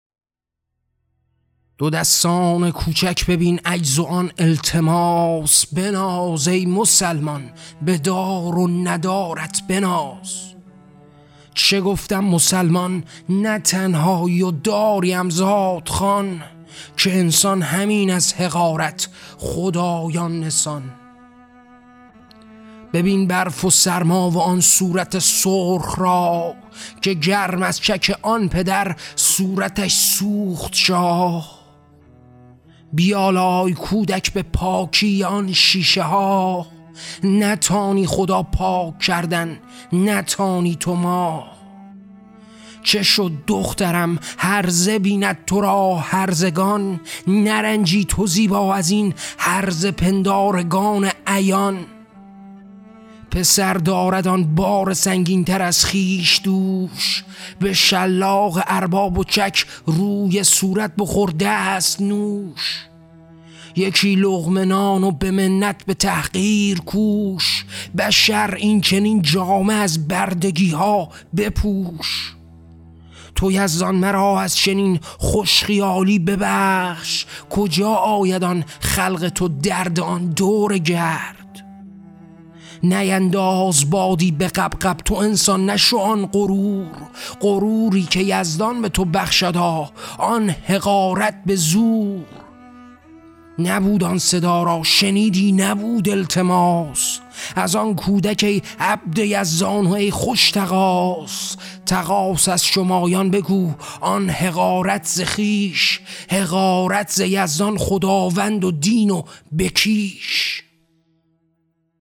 کتاب صوتی «رزم‌نامه»
این نسخه شنیداری با کیفیت استودیویی جهت غوطه‌وری کامل در مفاهیم اثر تهیه شده است.